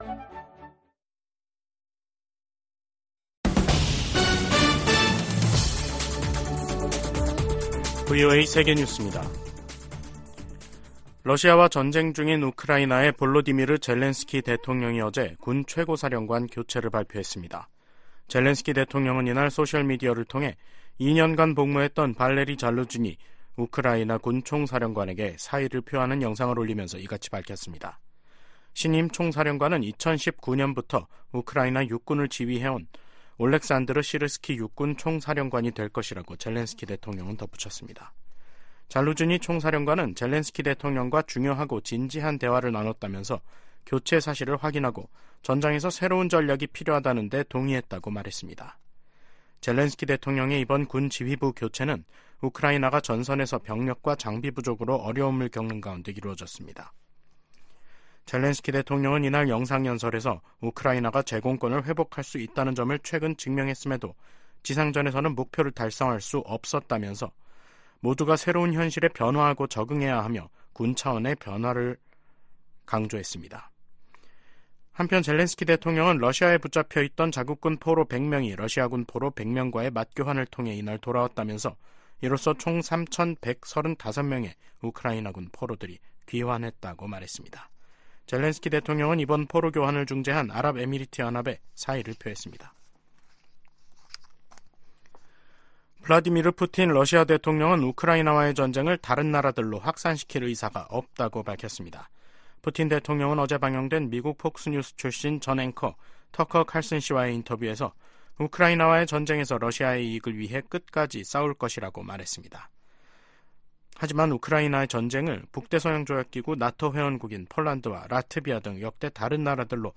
VOA 한국어 간판 뉴스 프로그램 '뉴스 투데이', 2024년 2월 9일 2부 방송입니다. 김정은 북한 국무위원장이 조선인민군 창건일인 8일 건군절 국방성 연설에서 한국을 제1 적대국가로 규정했다고 노동신문이 보도했습니다. 미 국무부는 북한 7차 핵실험 가능성을 경고한 주북 러시아 대사의 발언을 불안정하고 위태로우며 위험한 언행이라고 비판했습니다. 일부 전문가들이 한반도 전쟁 위기설을 제기한 가운데 미 국방부는 북한의 임박한 공격 징후는 없다고 밝혔습니다.